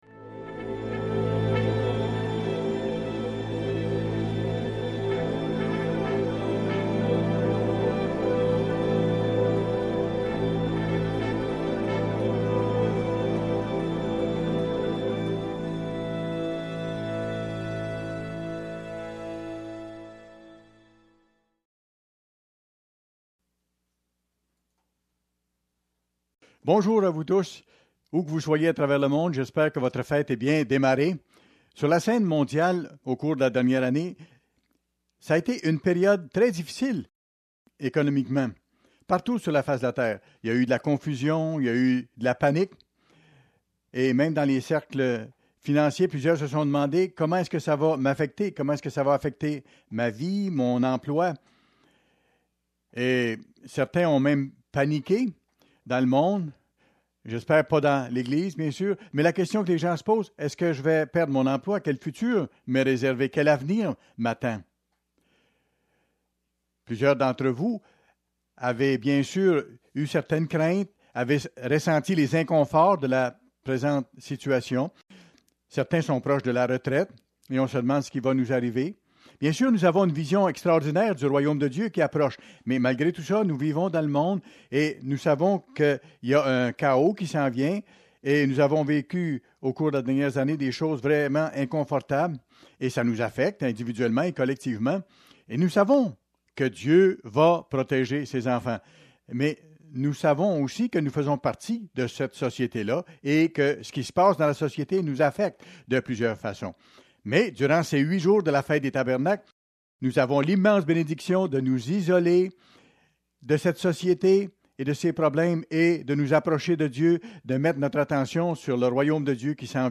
Fête des Tabernacles, 6ème jour